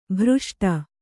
♪ bhřṣṭa